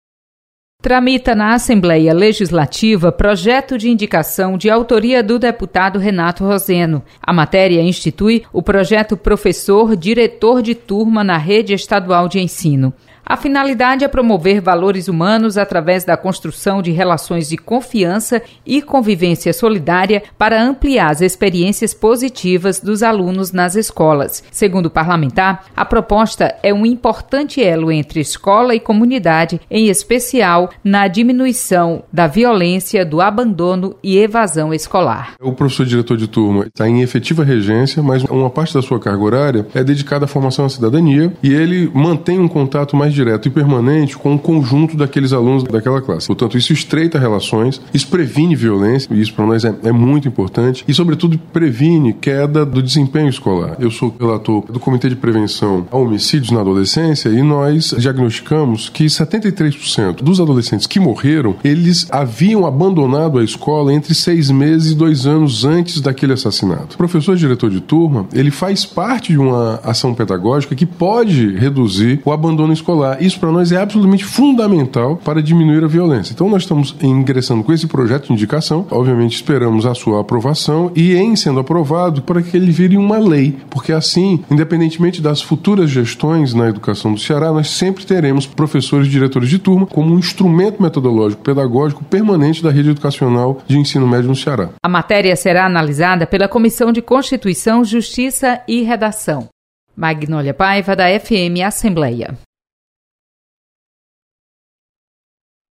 Deputado Renato Roseno propõe instituição de professor diretor der turma nas escolas estaduais. Repórter